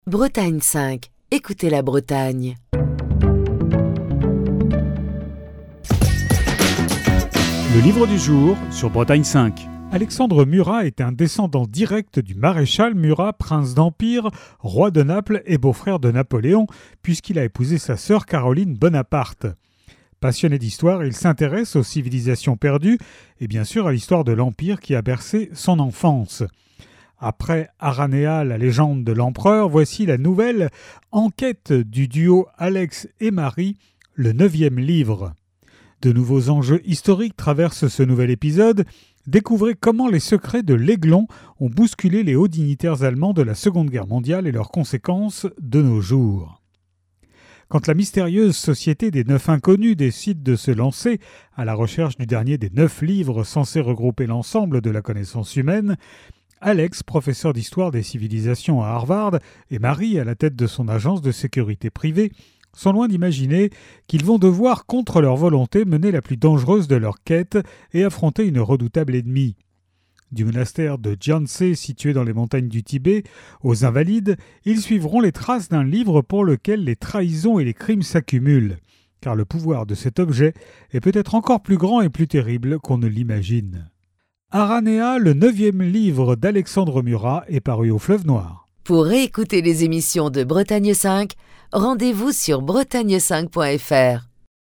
Chronique du 21 mai 2024.